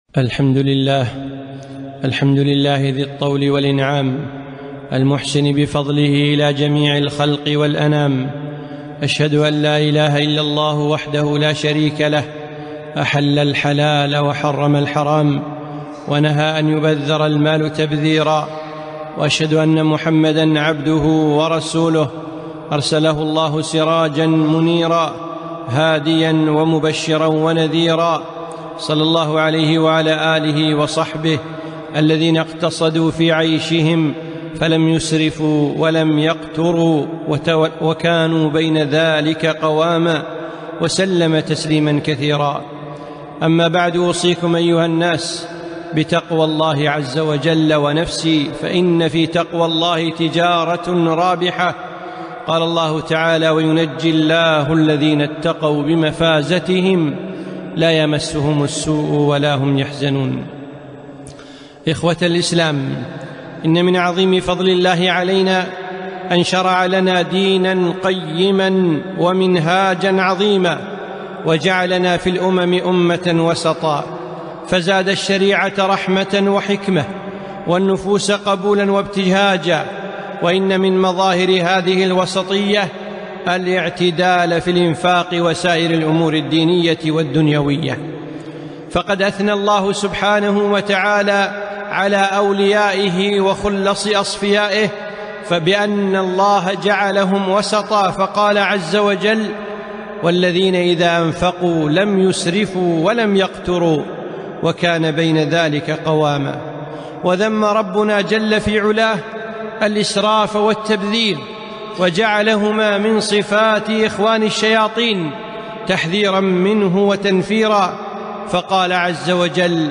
خطبة - ولا تـسـرفــوا